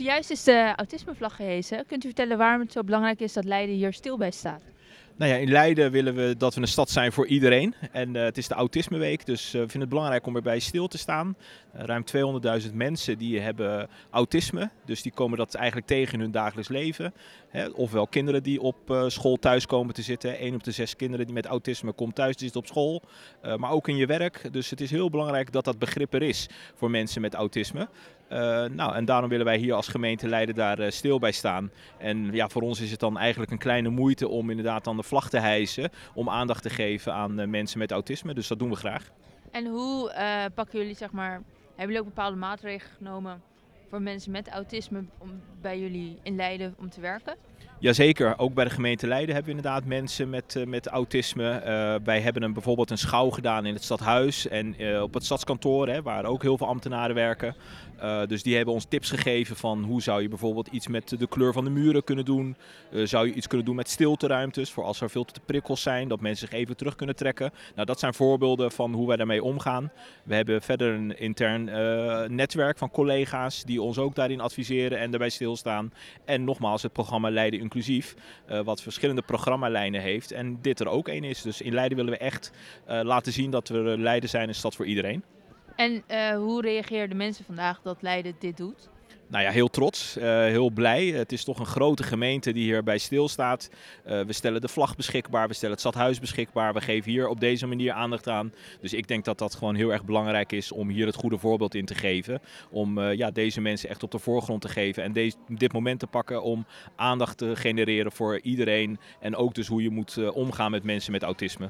interview-autisme-1.wav